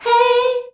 HEY     B.wav